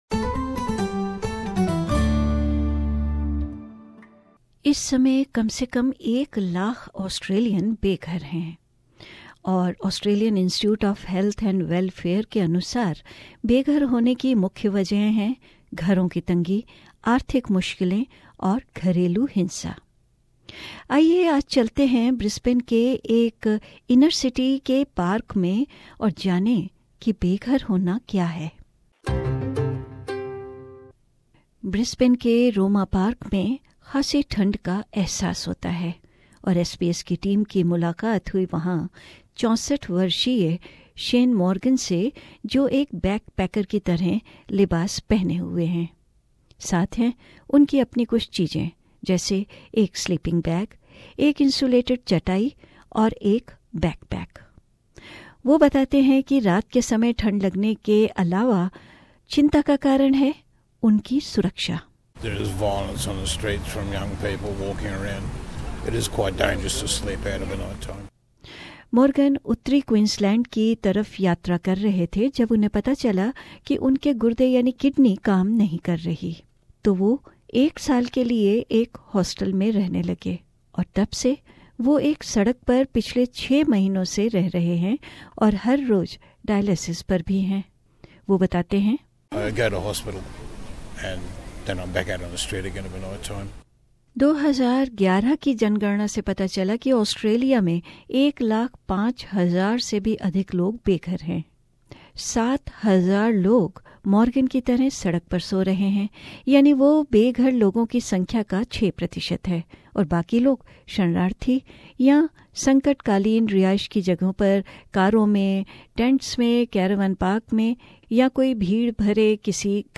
रिपोर्ट